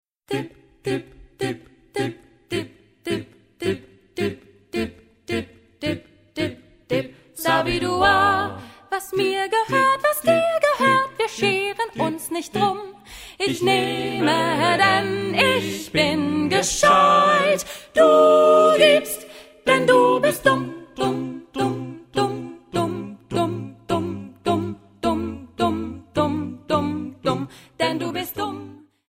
Genre-Style-Forme : Profane ; Jazz choral ; Chanson
Caractère de la pièce : mélodieux ; jazzy ; humoristique
Type de choeur : SATB  (4 voix mixtes )
Tonalité : do majeur